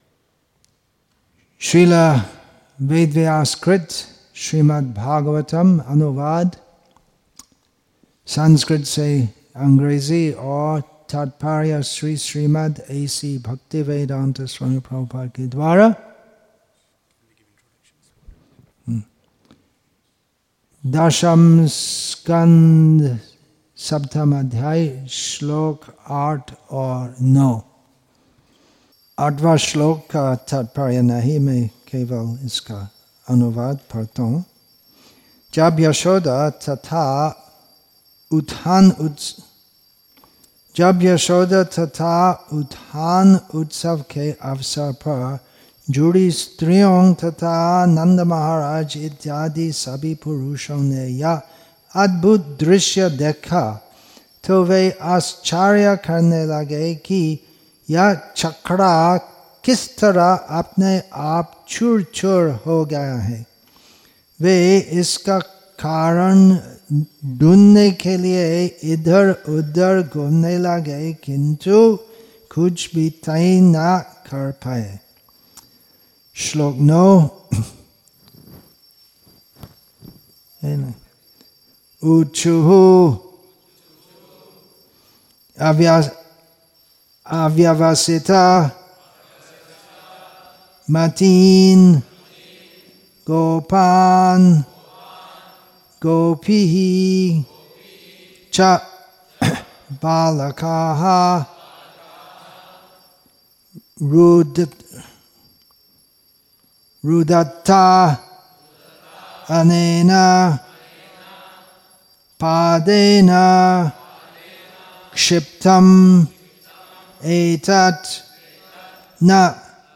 Ujjain, Madhya Pradesh , India